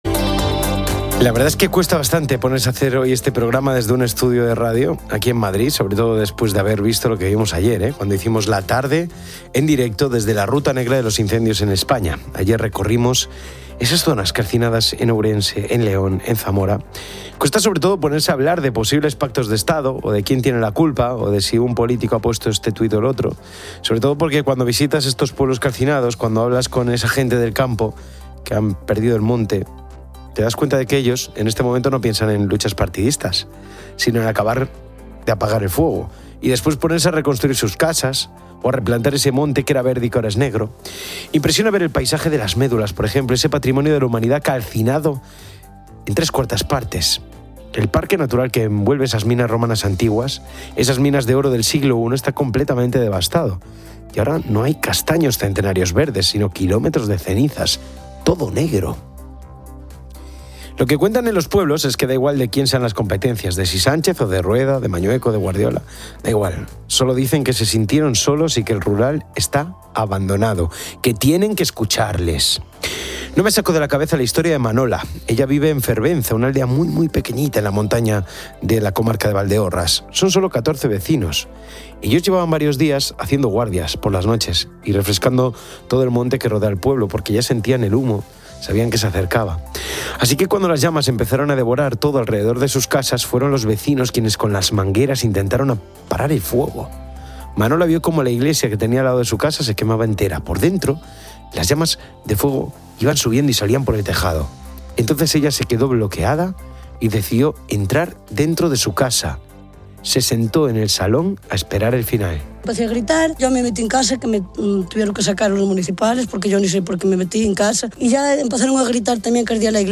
El locutor y los entrevistados critican la falta de atención política y el abandono del campo, enfatizando que el 80% de los incendios son provocados, calificándolos de "terrorismo".